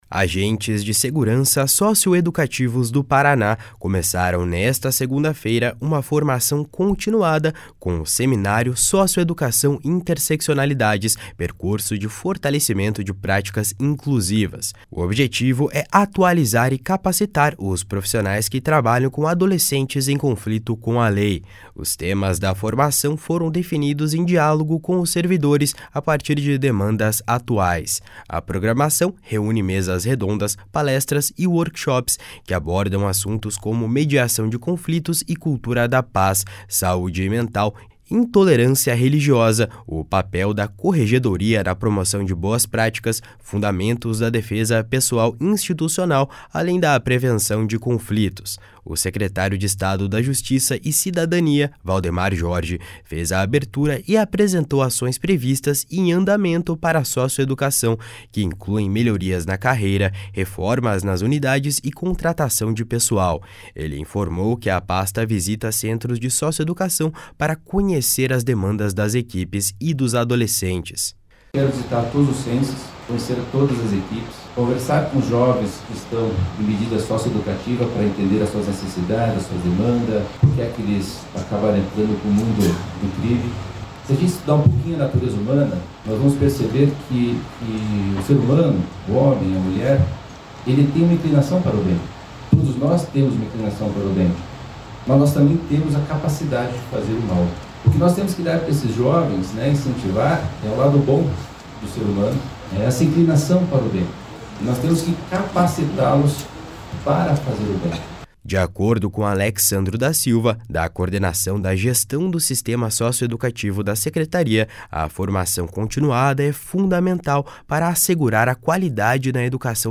O secretário de Estado da Justiça e Cidadania, Valdemar Jorge, fez a abertura e apresentou ações previstas e em andamento para a socioeducação, que incluem melhorias na carreira, reformas nas unidades e contratação de pessoal.
// SONORA VALDEMAR JORGE //